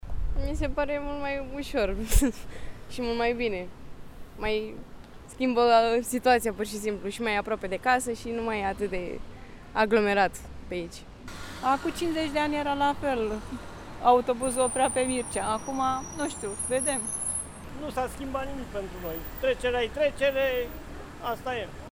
VOX | Trafic reorganizat pe strada Mircea cel Bătrân și b-dul Tomis. Reacțiile constănțenilor